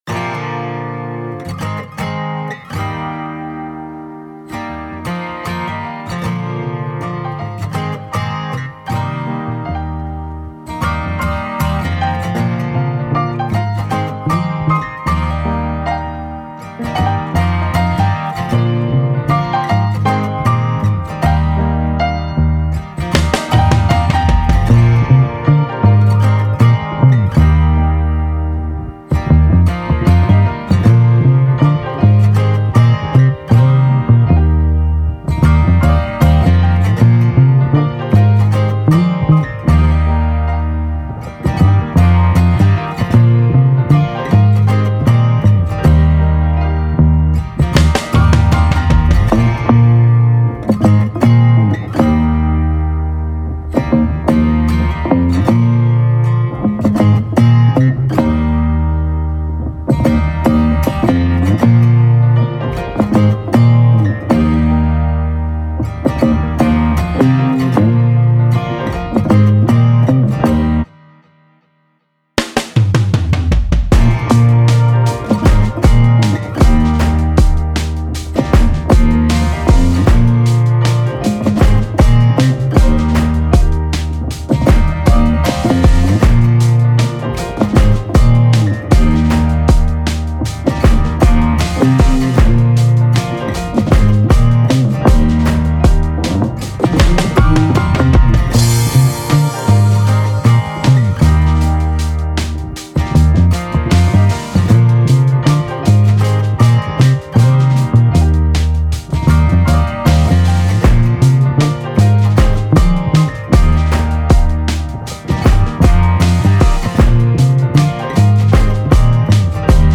official instrumental